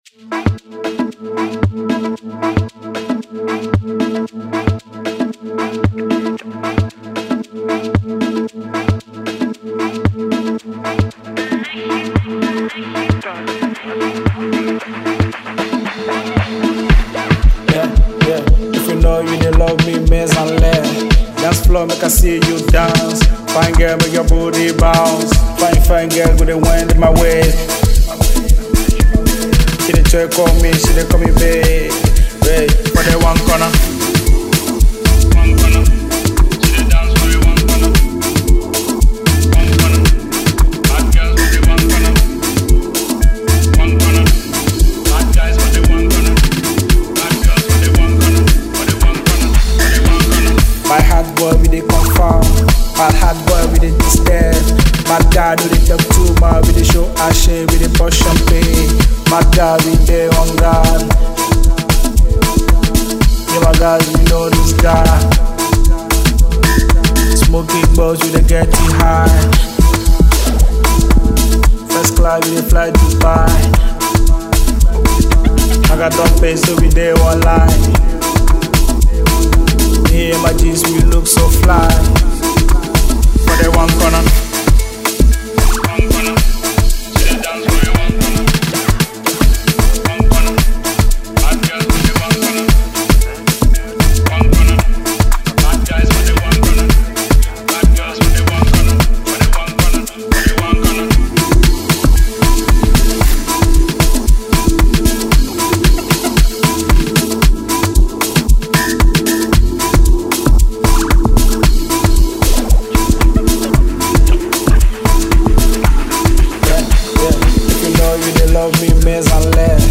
Amapiano track
With his distinct vocal style and energetic delivery